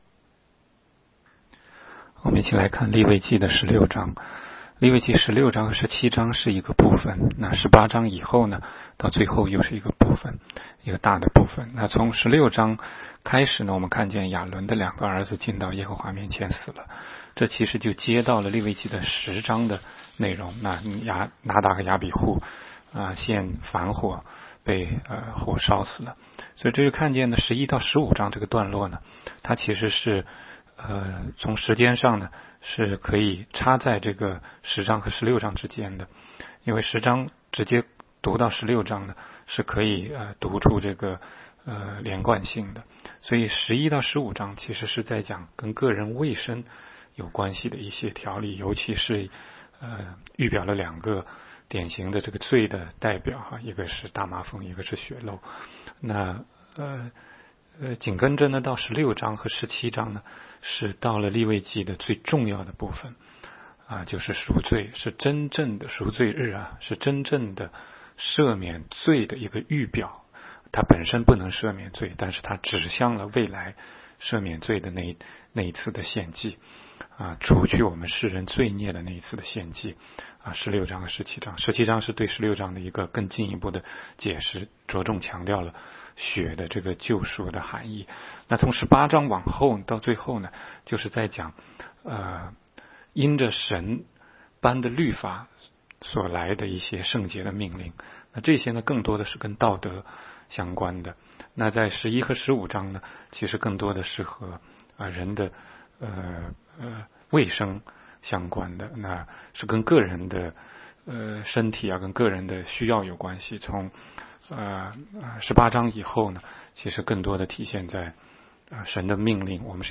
16街讲道录音 - 每日读经-《利未记》16章